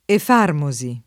[ ef # rmo @ i ]